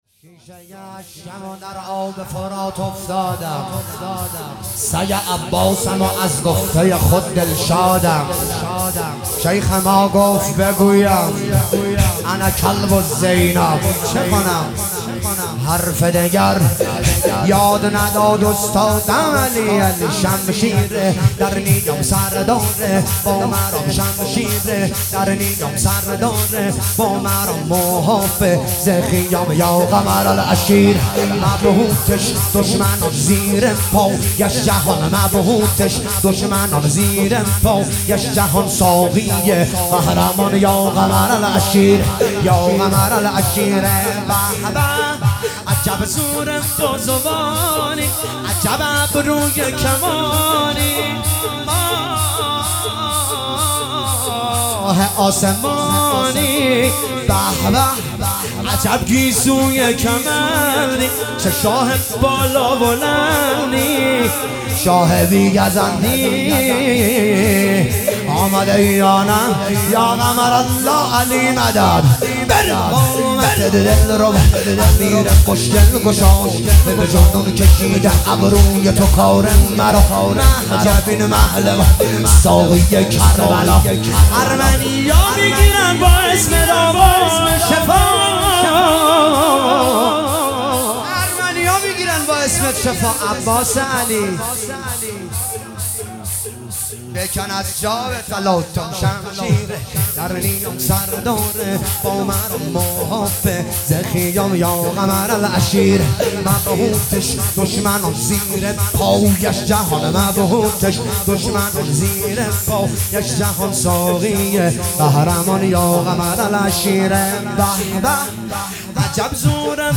فاطمیه اول 1403